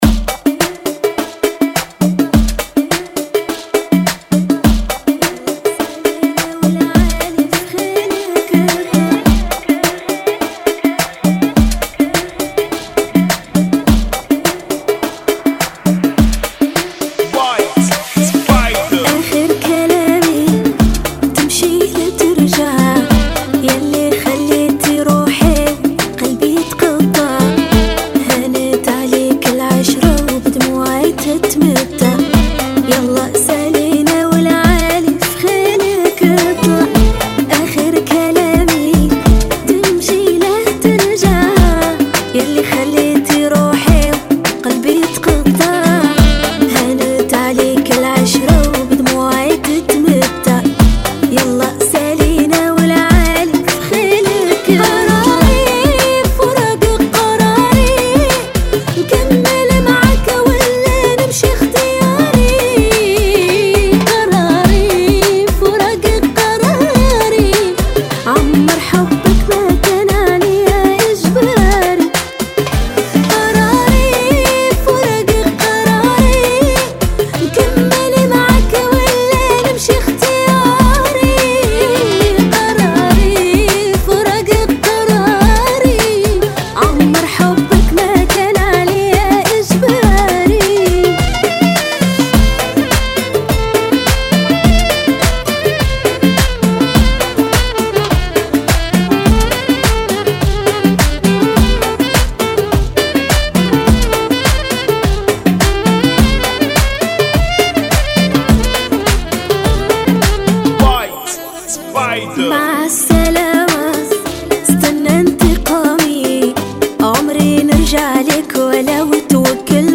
104 Bpm
Funky